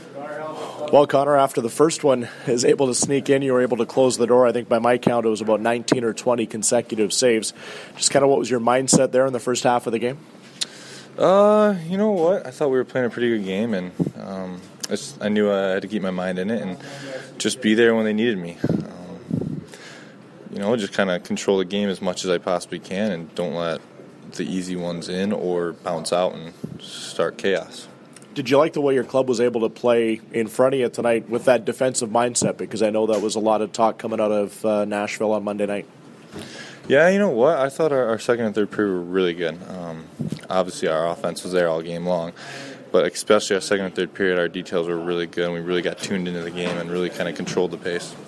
Post-game from the Jets dressing rooms as well as from Coach Maurice.
Post-game audio: